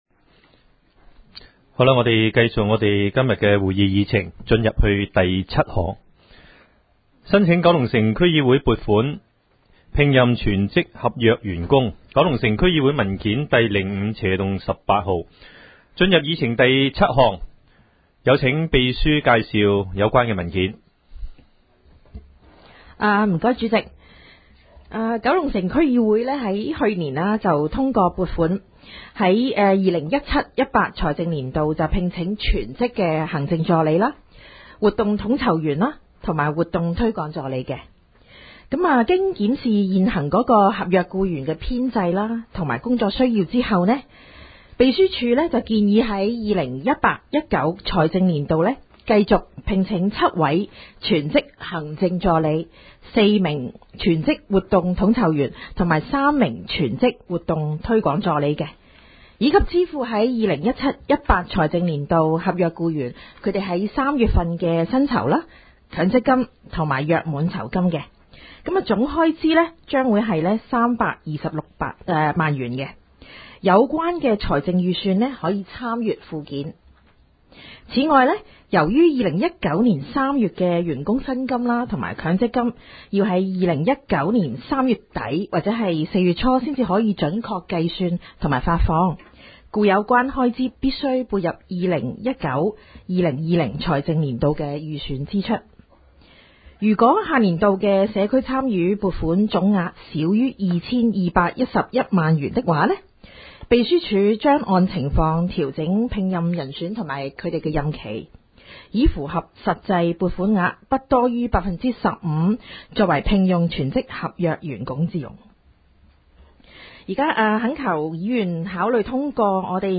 区议会大会的录音记录
九龙城民政事务处会议室